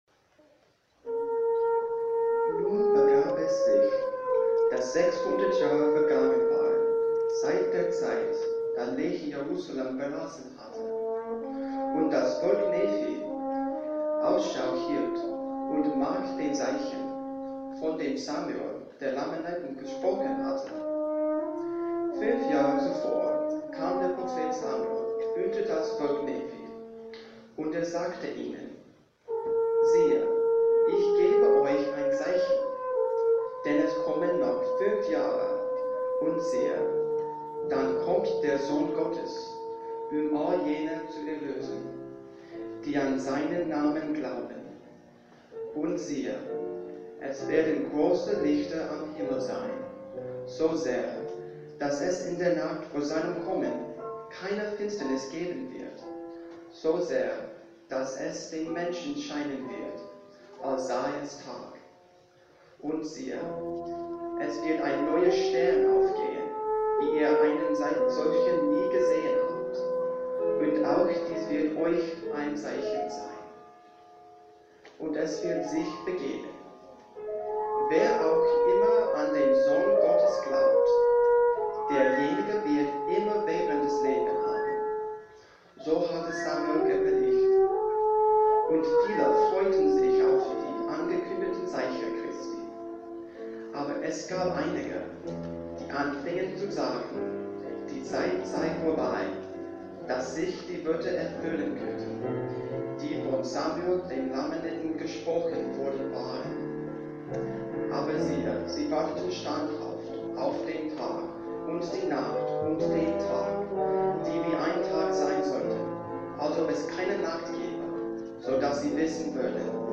Soprano, SATB, Narrator, Horn, and Piano